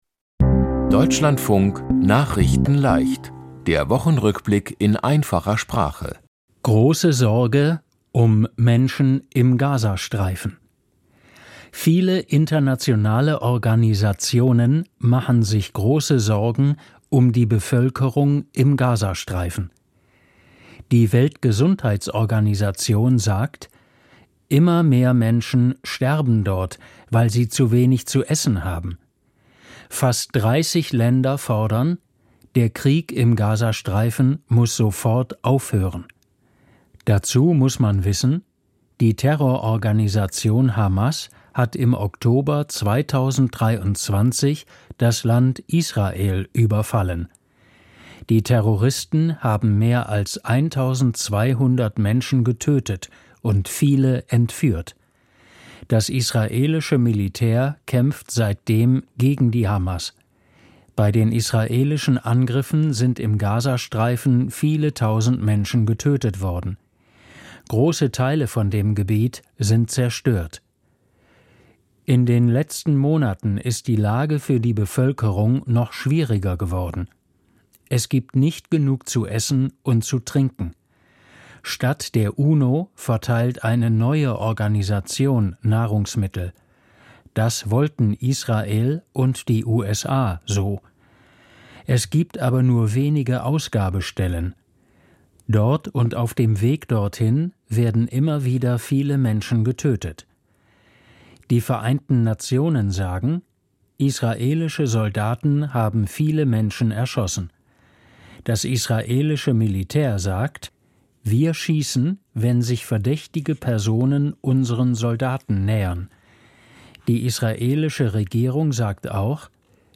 Die Themen diese Woche: Große Sorgen um Menschen im Gazastreifen, Wichtiges Gutachten zum Klima-Schutz, Jesidische Familie mit 4 Kindern in das Land Irak abgeschoben, Sänger Ozzy Osbourne ist tot und Deutschland bei Frauen-Fußball-EM ausgeschieden. nachrichtenleicht - der Wochenrückblick in einfacher Sprache.